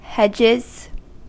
Uttal
Uttal US Ordet hittades på dessa språk: engelska Ingen översättning hittades i den valda målspråket.